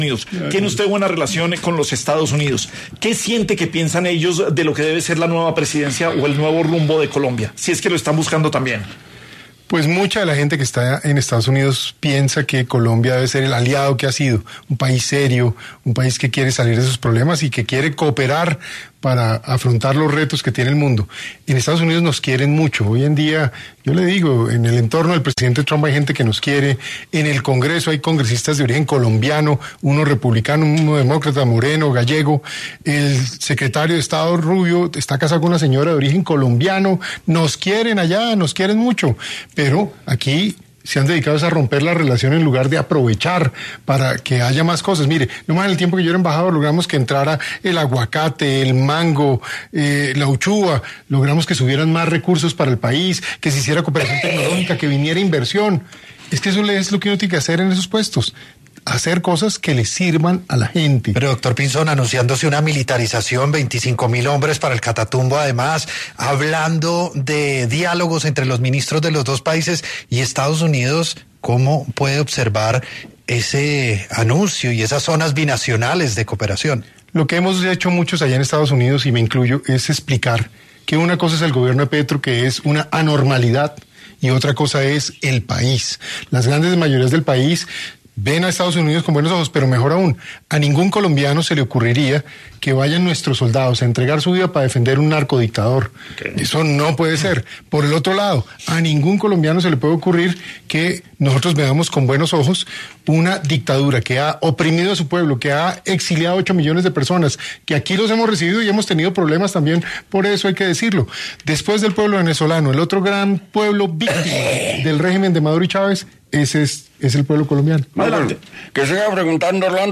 El exministro de Defensa y precandidato presidencial Juan Carlos Pinzón, pasó por los micrófonos de Caracol Radio en la Luciérnaga, para revelar cómo están las relaciones entre Estados Unidos y Colombia.